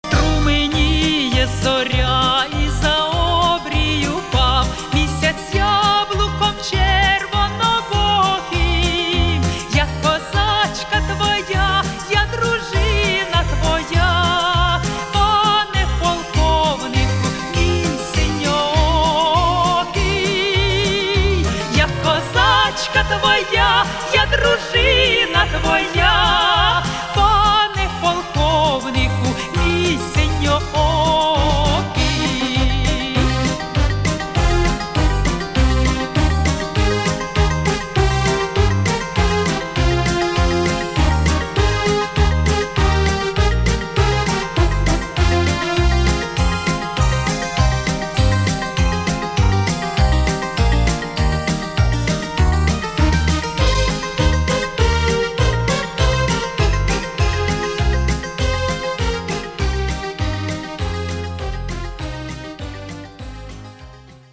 • Качество: 320, Stereo
украинские
патриотические